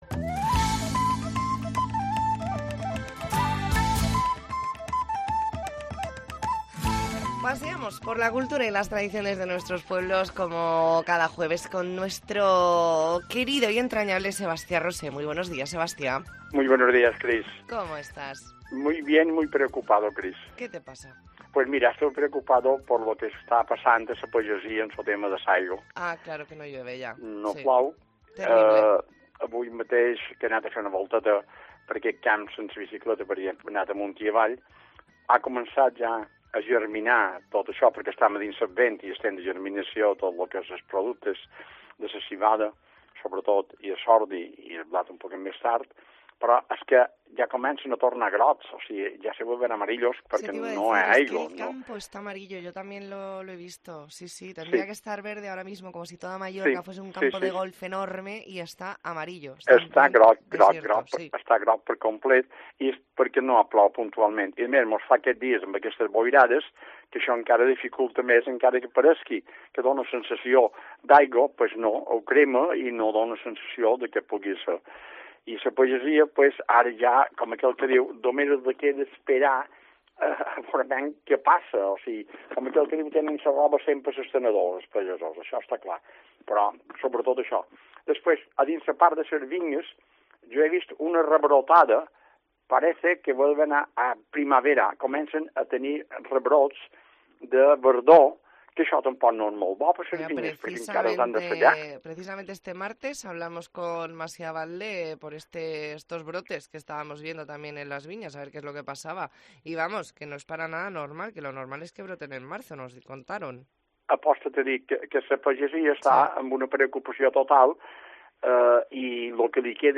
Espacio semanal donde repasamos las costumbres mallorquinas y os contamos las ferias y fiestas de los próximos días. Entrevista en 'La Mañana en COPE Más Mallorca', jueves 14 de noviembre de 2023.